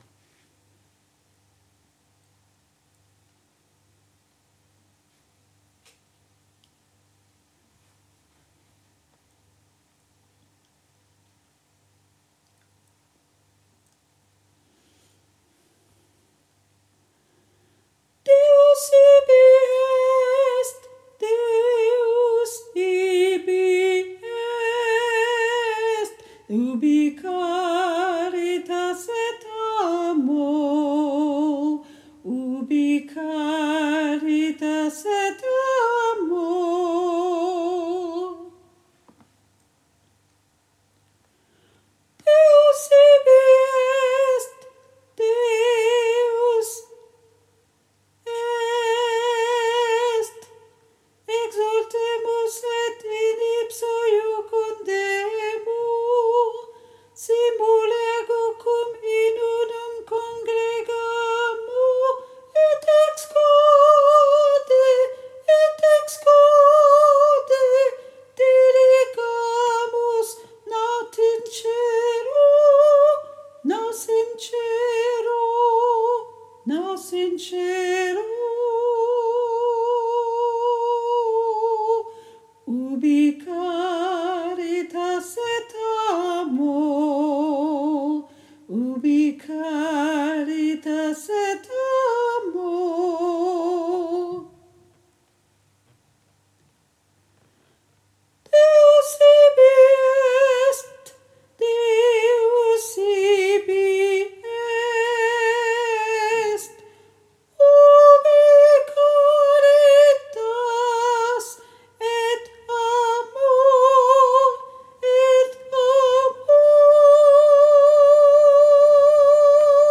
MP3 versions chantées
Ténor